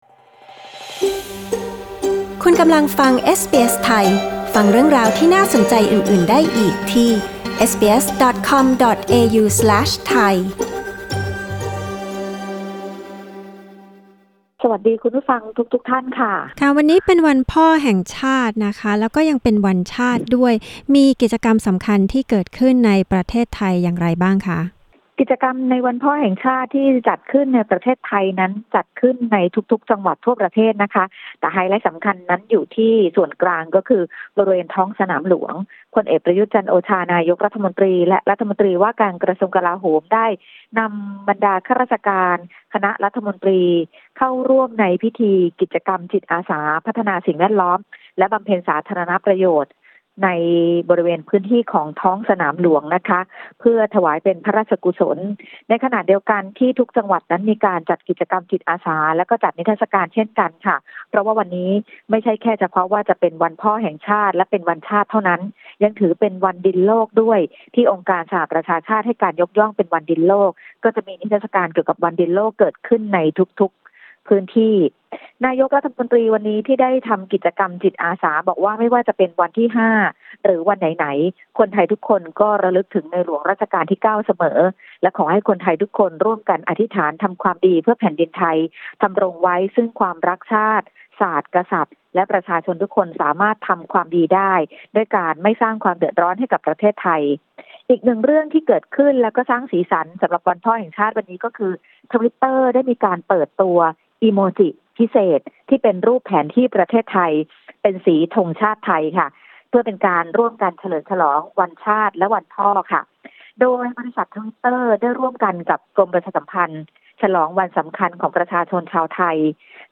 กดปุ่ม 🔊 ด้านบนเพื่อฟังรายงานข่าว